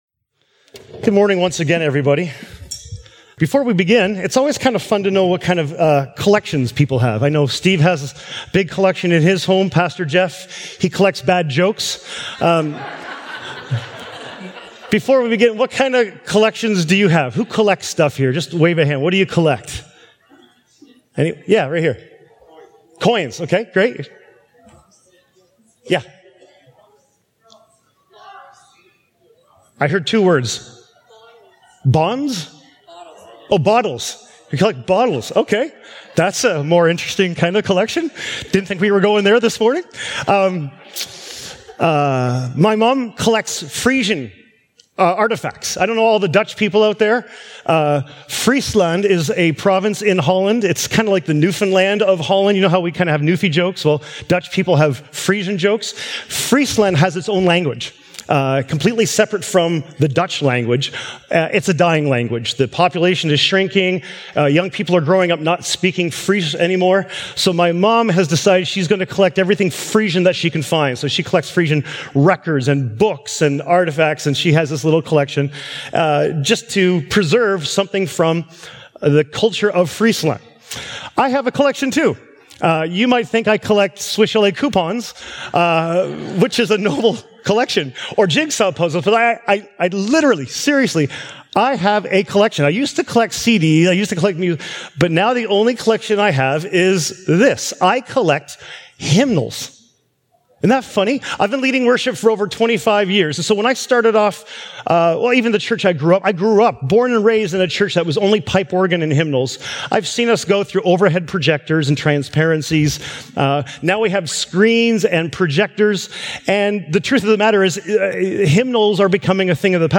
Sermons | Emmanuel Church